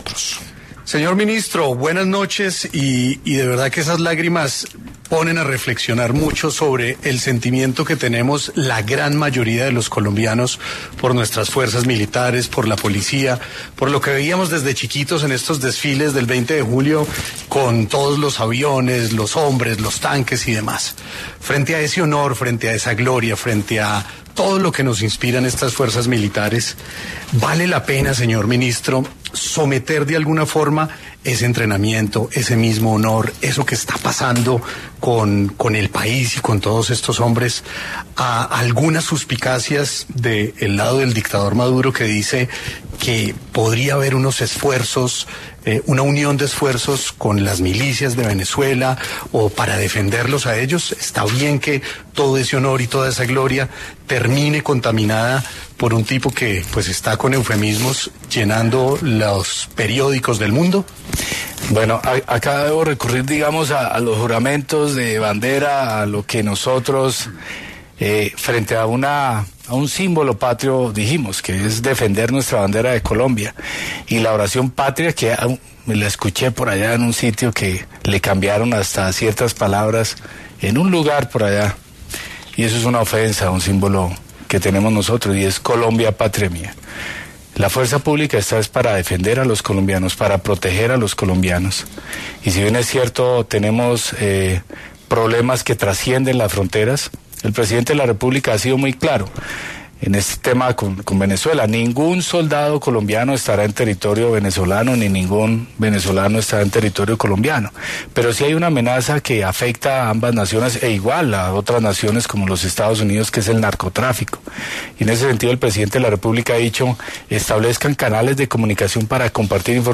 El Ministro de Defensa, Pedro Sánchez, habló del uso de las fuerzas públicas en Sin Anestesia de Caracol Radio